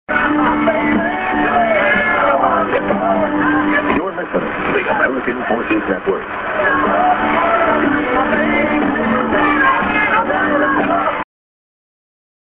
via Guam(Night Time)